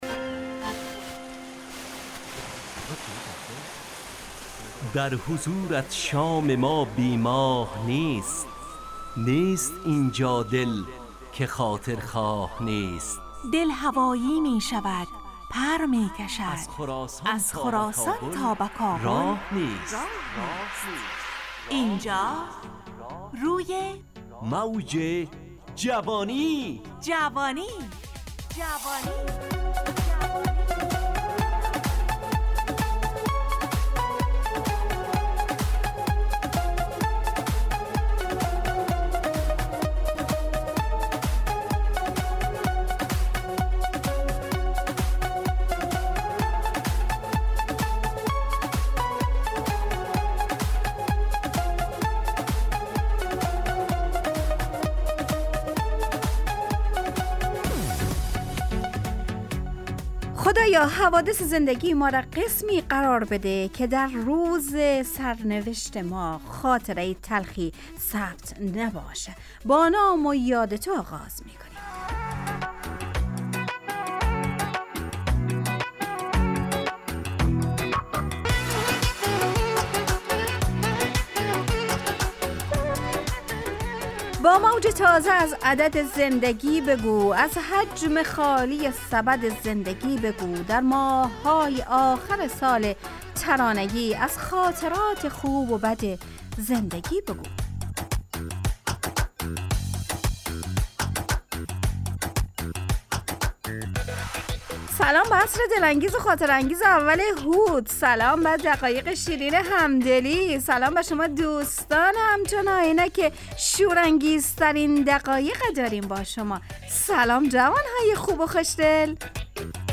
همراه با ترانه و موسیقی مدت برنامه 70 دقیقه . بحث محوری این هفته (آیینه)
روی موج جوانی برنامه ای عصرانه و شاد